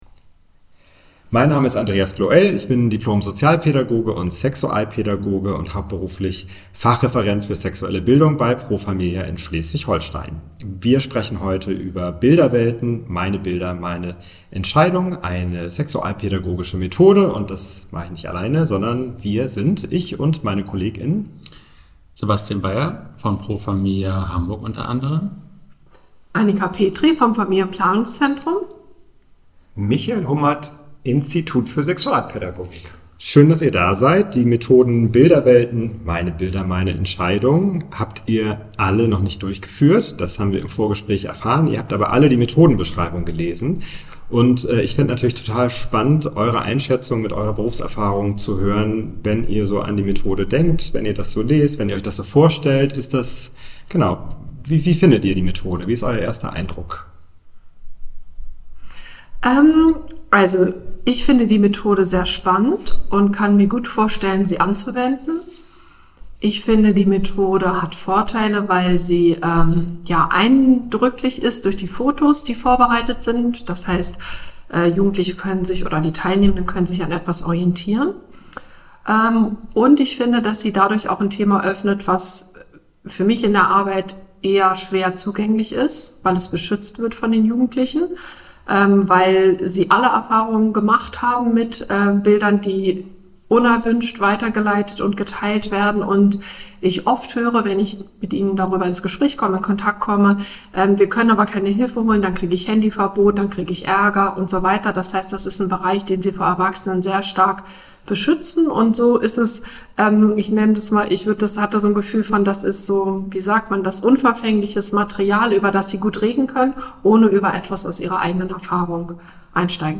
Podcast_Methodendiskussion_Bilderwelten.mp3